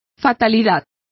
Complete with pronunciation of the translation of dooms.